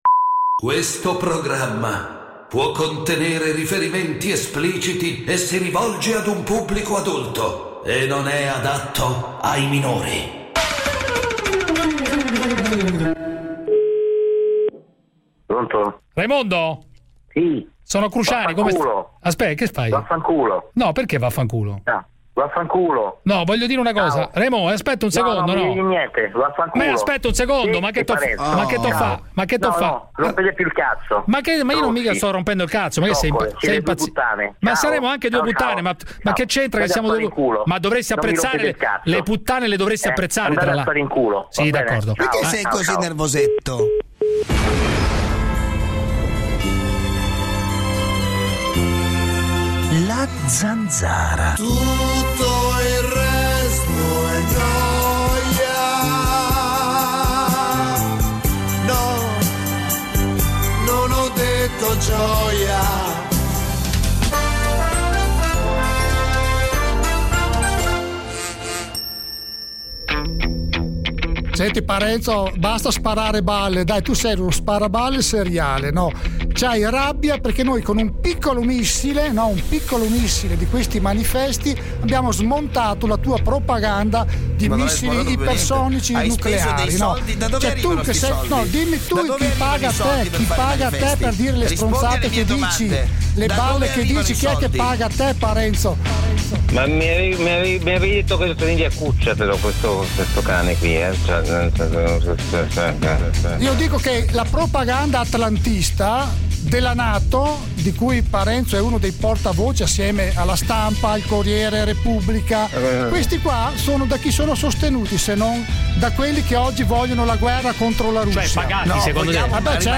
Giuseppe Cruciani con David Parenzo conduce "La Zanzara", l'attualità senza tabù, senza censure, senza tagli alle vostre opinioni. Una zona franca per gli ascoltatori, uno spazio nemico della banalità e del politicamente corretto, l'arena dove il primo comandamento è parlare chiaro.